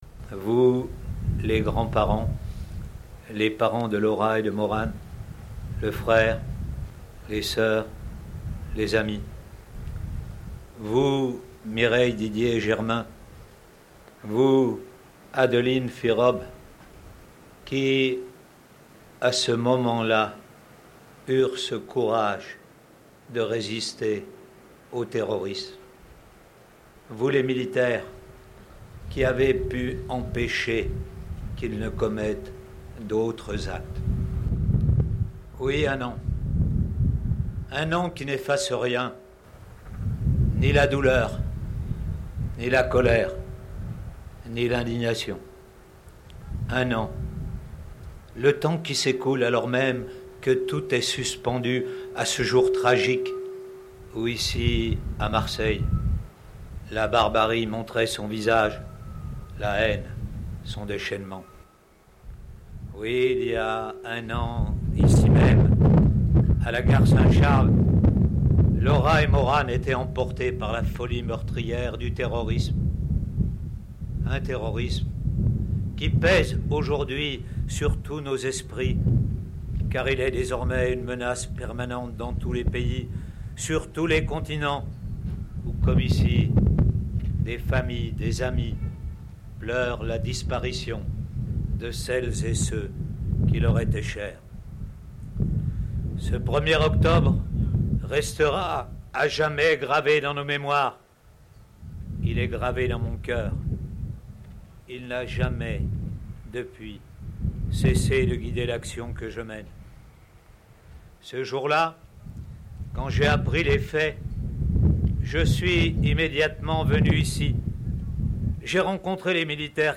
son_copie_petit-282.jpg Le ministre de l’Intérieur, Gérard Collomb revient lors de son discours sur «un terrorisme qui pèse aujourd’hui sur tous nos esprits car il est désormais une menace permanente dans tous les pays, sur tous les continents où comme ici des familles, des amis pleurent la disparition de celles et ceux qui leur étaient chers.